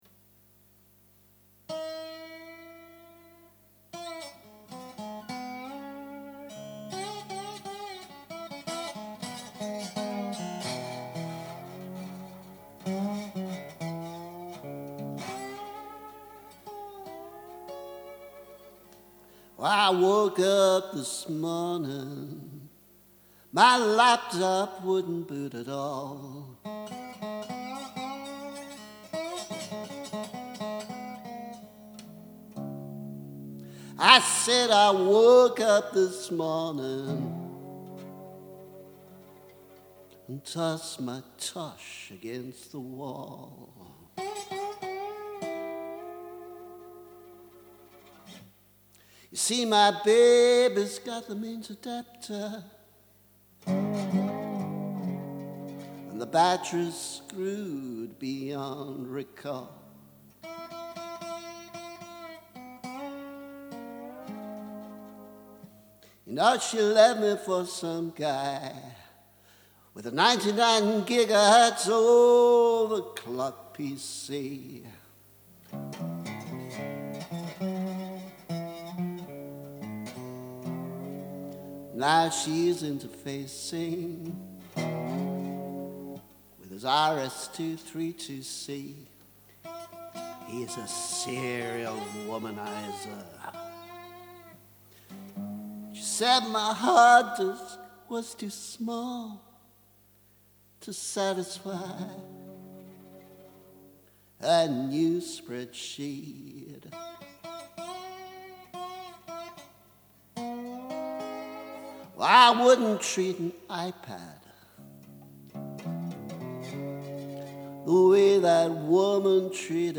Slide version: